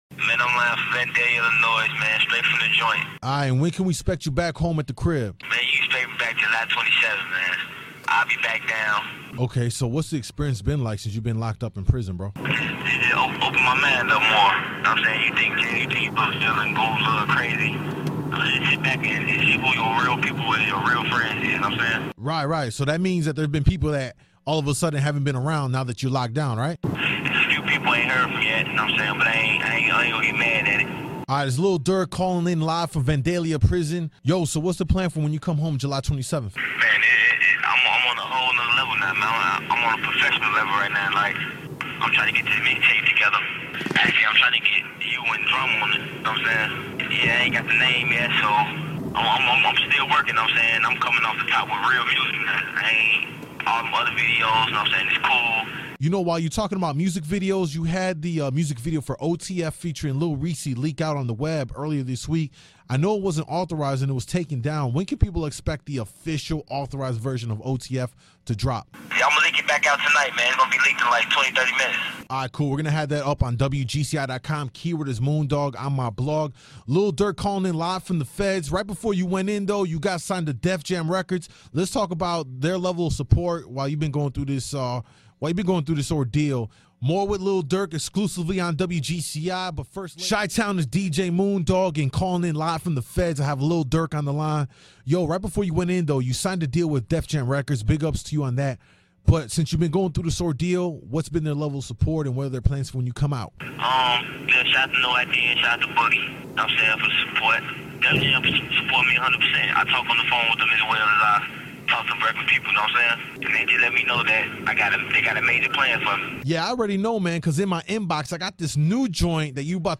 Lil Durk Calls Into WGCI